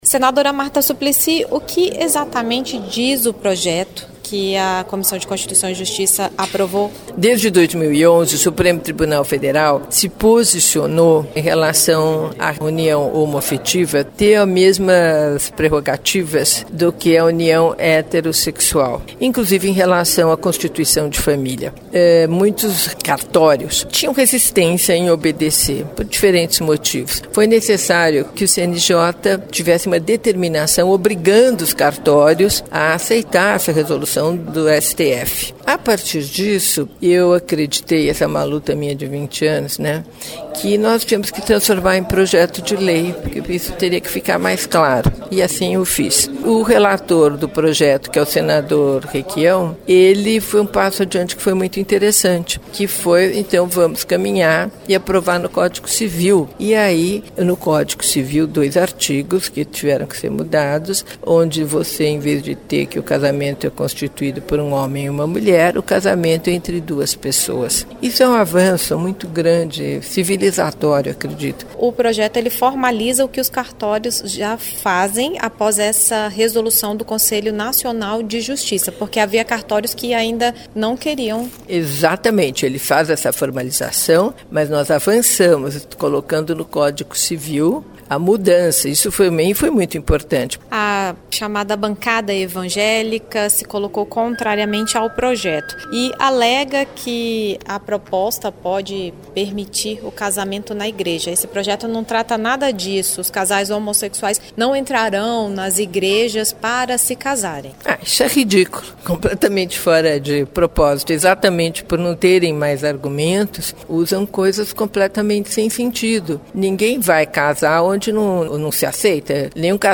Marta Suplicy negou que a proposta trate do casamento religioso, como alegam parlamentares da bancada evangélica. Ouça o áudio da Rádio Senado.